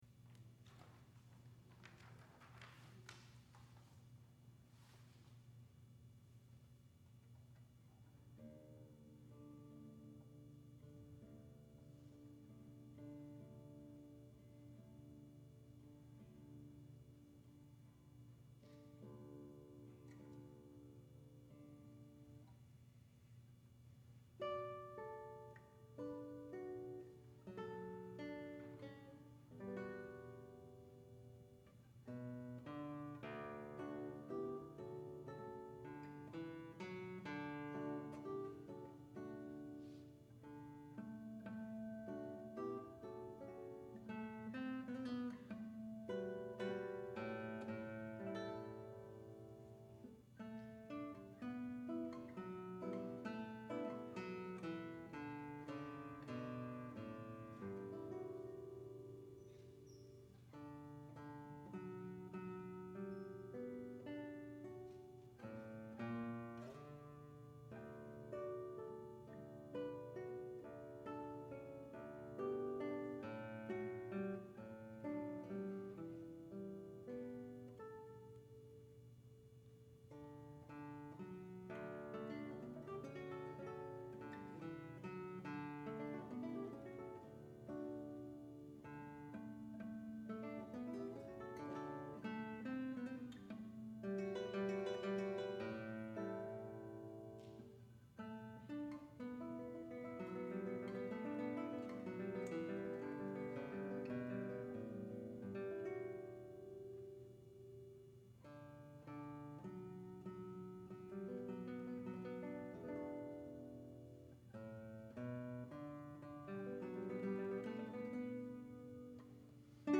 for Guitar (2006)
The guitar emphasizes this heaviness with glissandi.